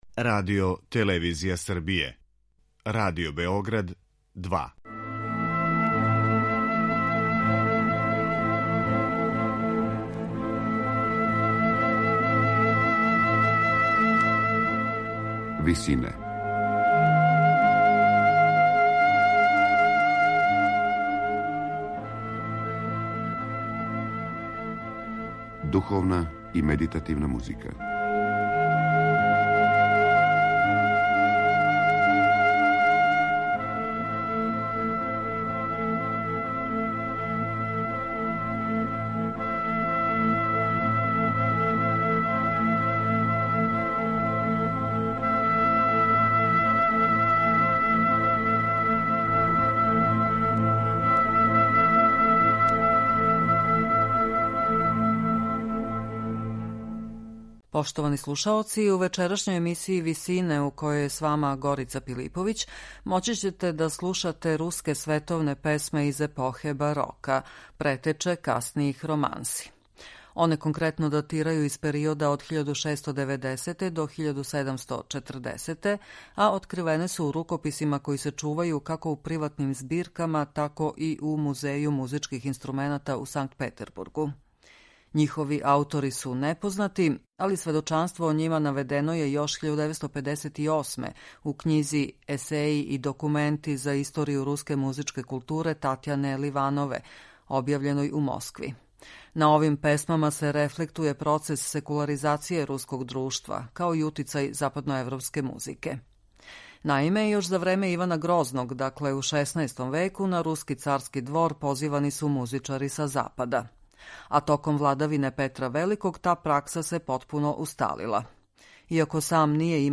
Извођачи су чланови ансамбла Синтагма
Руске барокне романсе